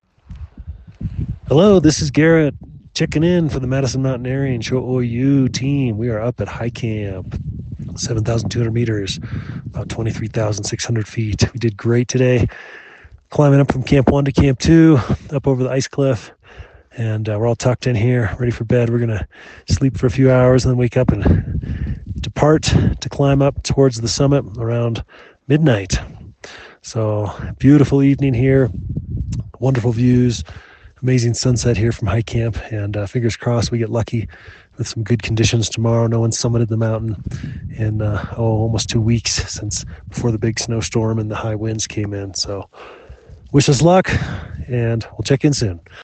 checks in with this pre-summit push dispatch from High Camp on Cho Oyu: